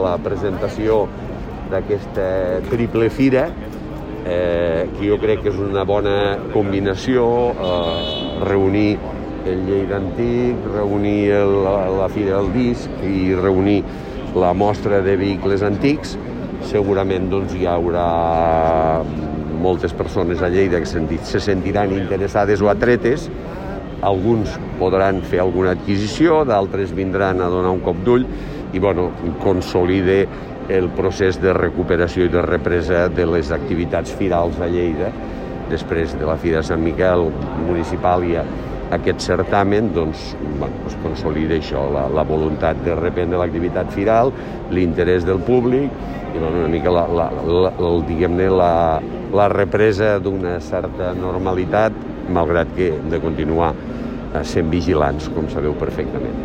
tall-de-veu-del-paer-en-cap-miquel-pueyo